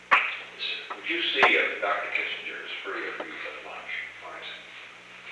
Secret White House Tapes
Conversation No. 735-6
Location: Oval Office